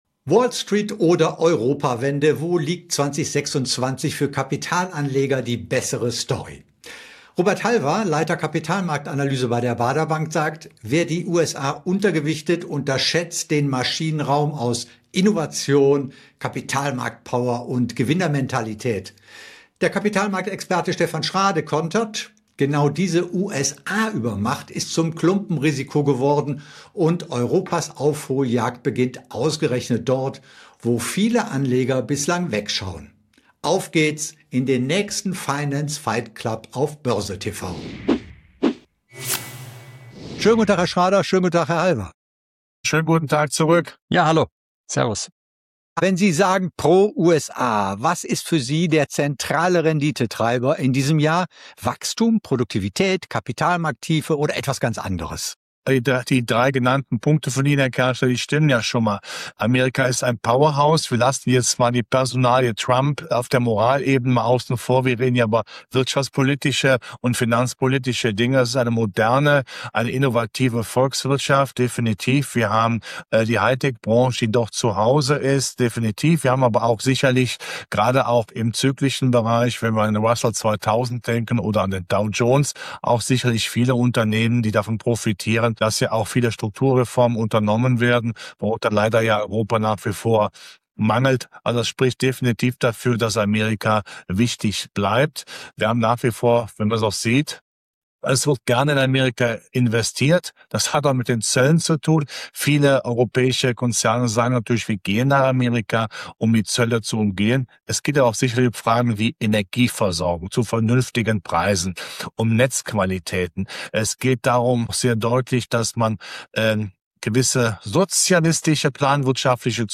Diskussion